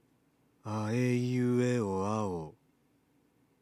地声の機能があまり働いていない喋り声
音量注意！